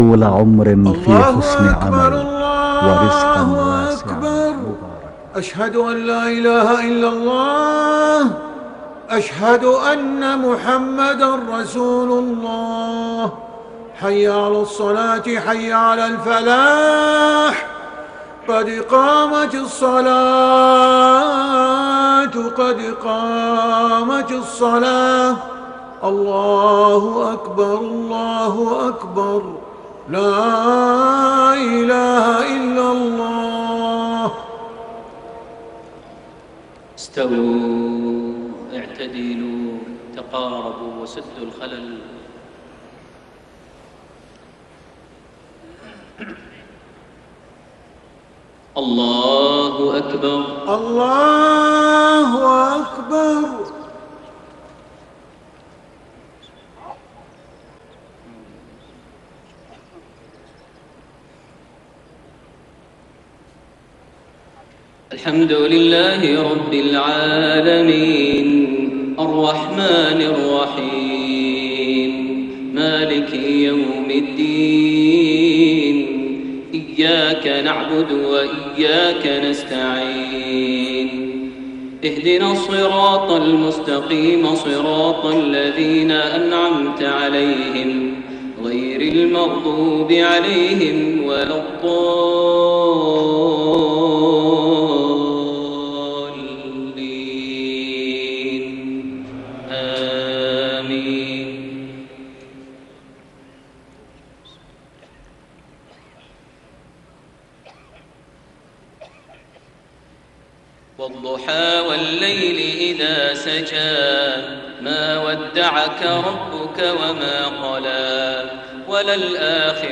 صلاة المغرب 8 ذو القعدة 1432هـ سورتي الضحى و الكوثر > 1432 هـ > الفروض - تلاوات ماهر المعيقلي